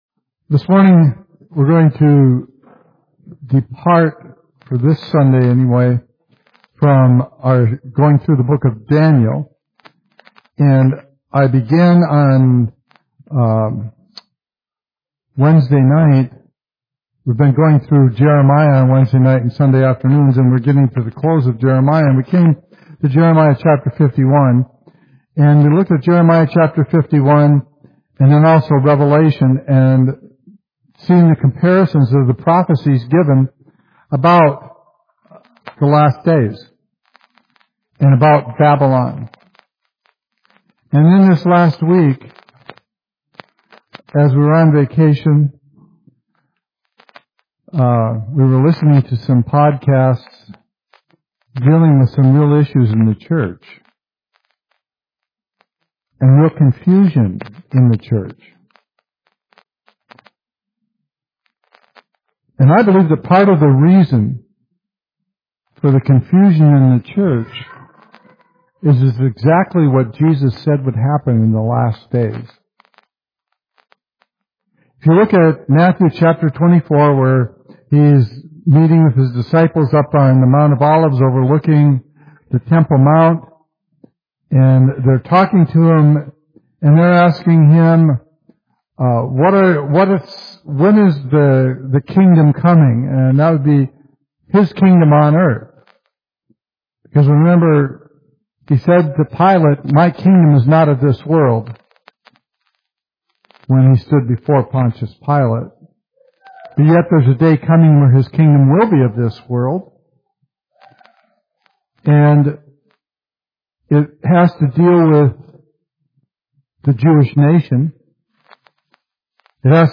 Discover how the Spirit of Babylon continues to shape our world today. A biblical study exposing spiritual deception and calling believers to stand firm in truth.